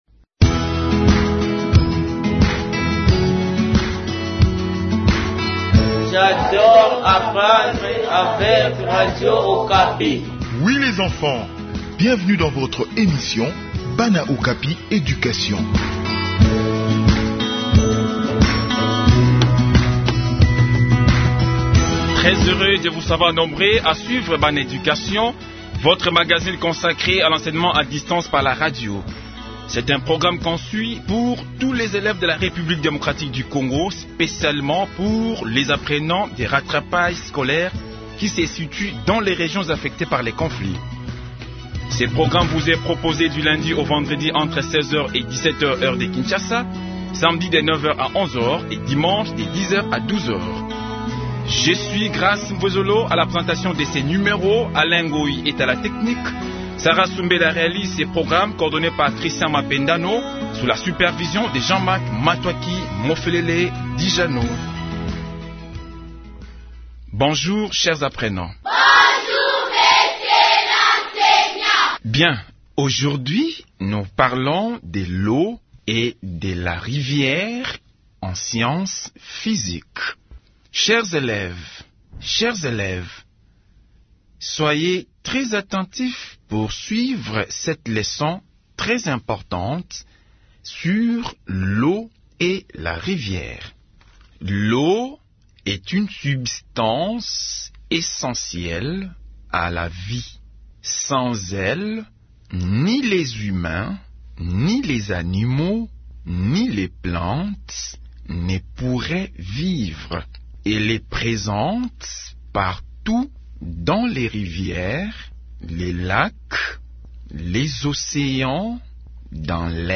Enseignement à distance : leçon de physique sur l’eau.